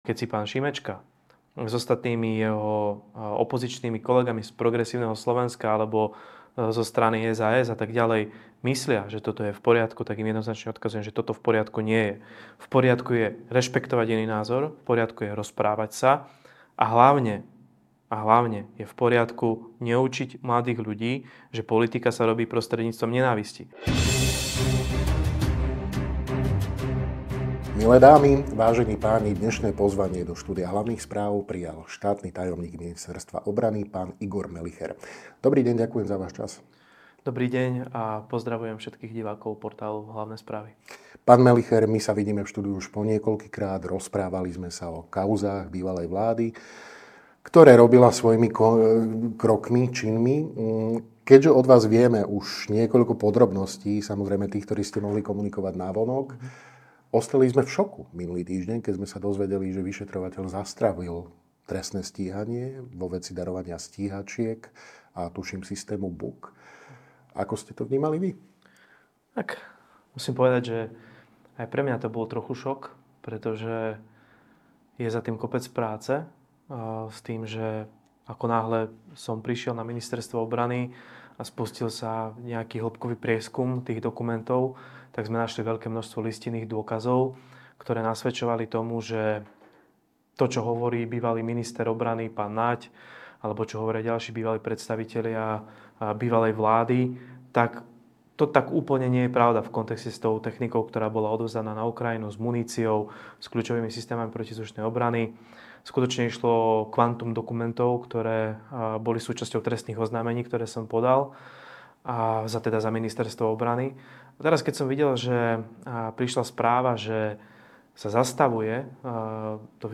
Okrem tejto témy sme sa vo videorozhovore so štátnym tajomníkom Ministerstva obrany SR, Mgr. Igorom Melicherom, venovali aj normalizácii hrubých vulgarizmov vo verejných prejavoch a ich následným dopadom na spoločnosť, vrátili sme sa k debate premiéra Fica so študentmi v Poprade a zhodnotili sme „oslavy“ 17. novembra, ktoré sa opäť raz zvrhli na demonštrácie v duchu „dosť bolo Fica“.